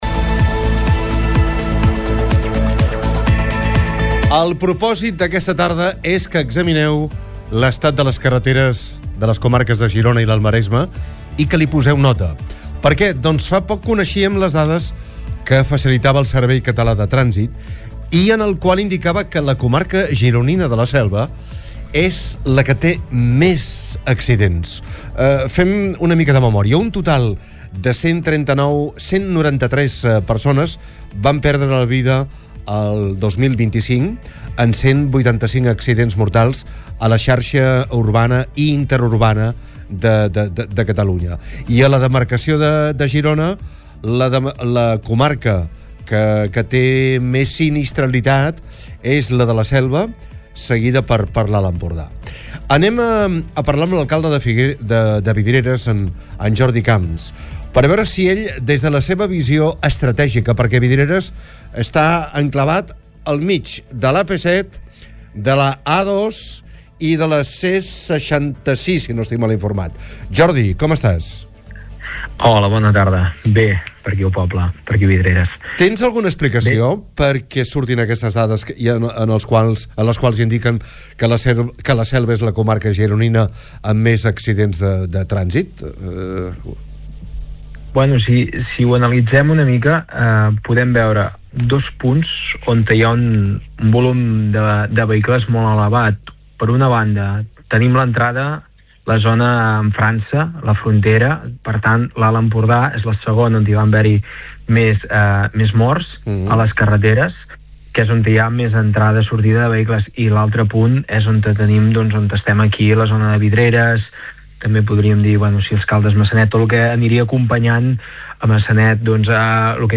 ha entrevistat a l’alcalde de Vidreres